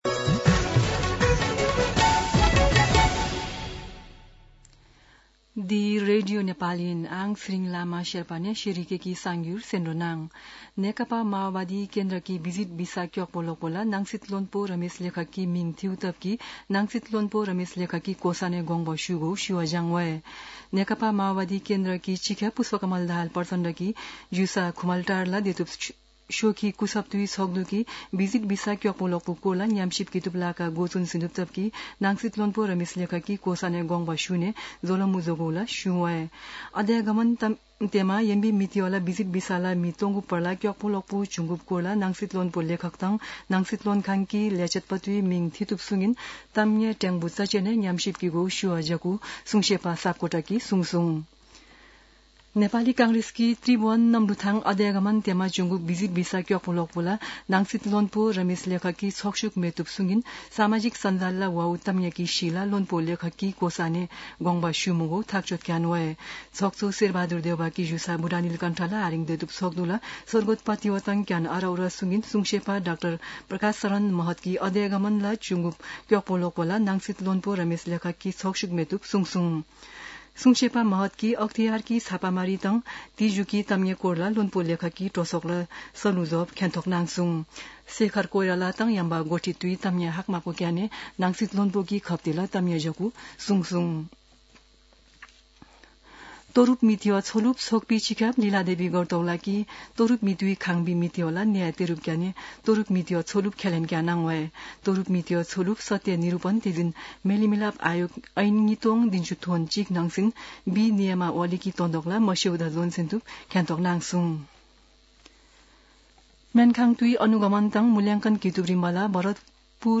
शेर्पा भाषाको समाचार : १२ जेठ , २०८२